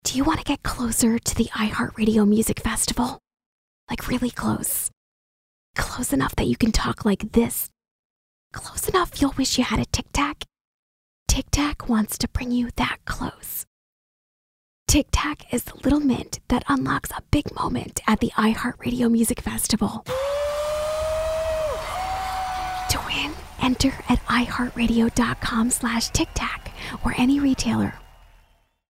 tictac03whisper-rev.mp3